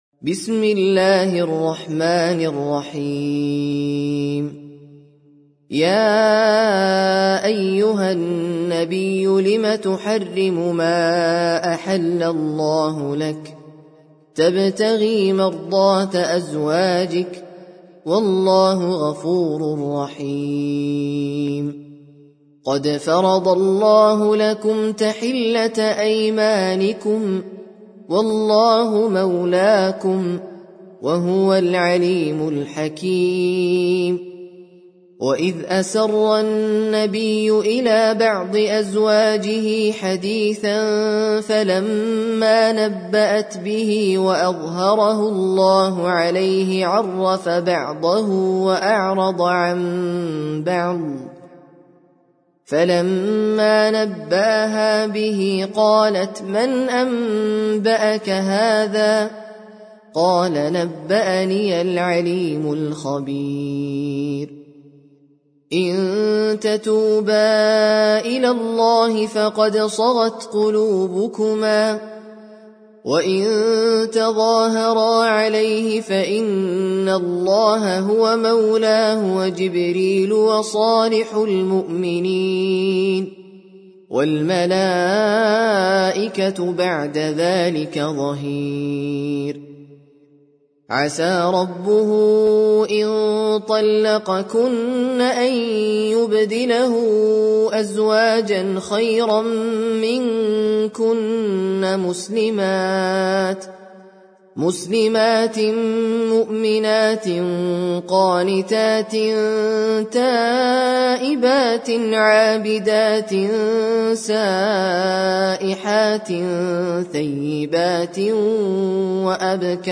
Sûrat At-Tahrim (The Prohibition) - Al-Mus'haf Al-Murattal (Narrated by Hafs from 'Aasem)
very high quality